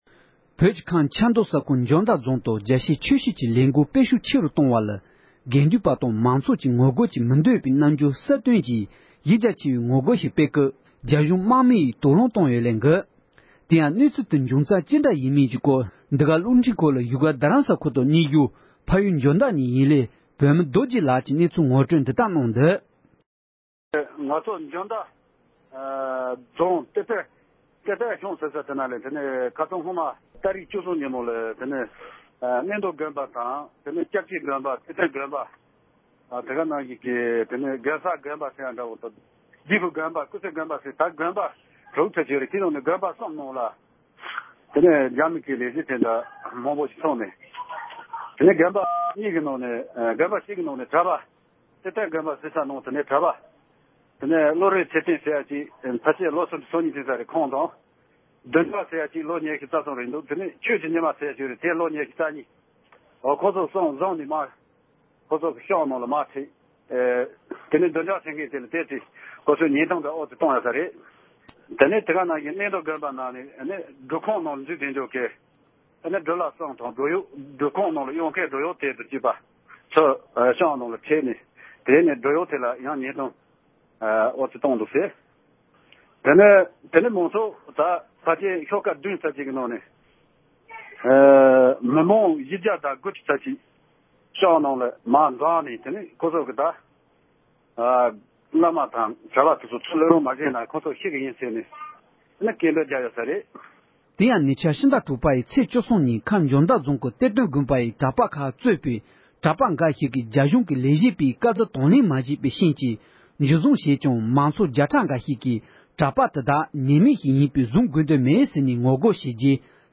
འབྲེལ་ཡོད་མི་སྣར་བཀའ་འདྲི་ཞུས་པ་ཞིག་ལ་གསན་རོགས་ཞུ།